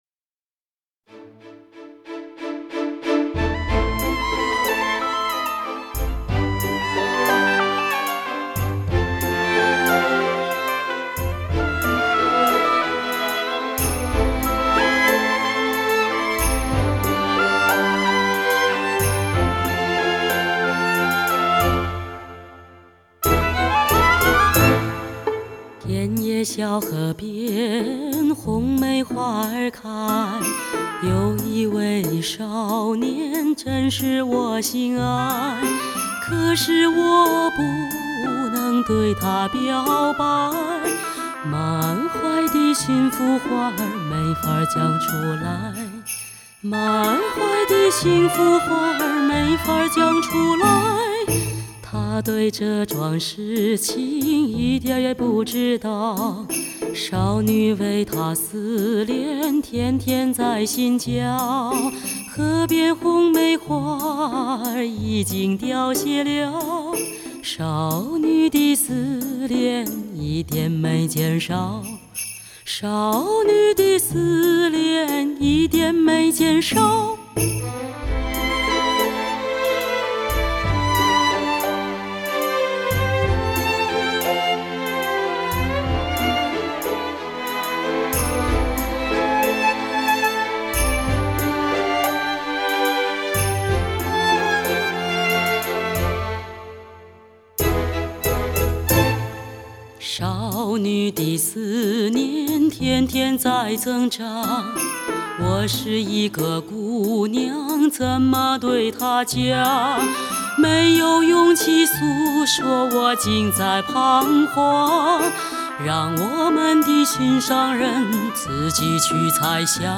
DTS多声道，尽显音乐魅力